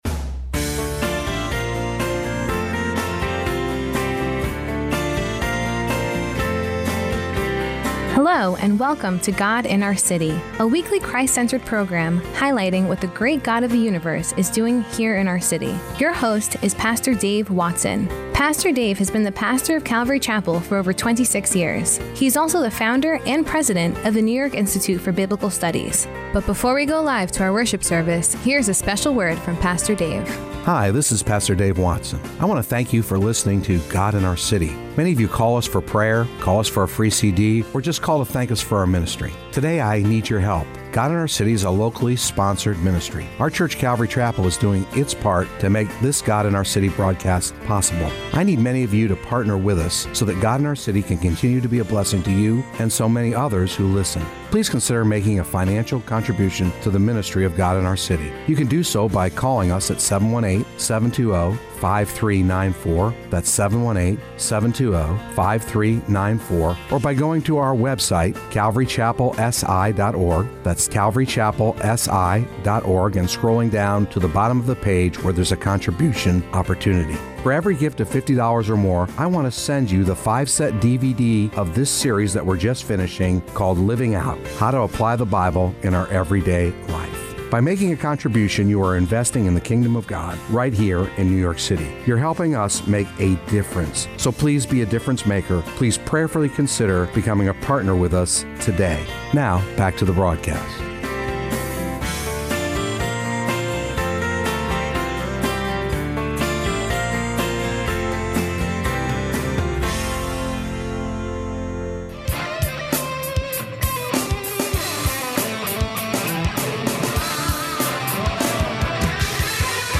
This past week’s podcast from our “God in Our City” broadcast, March 19, 2017.